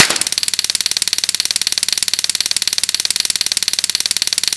rptstungunmain.mp3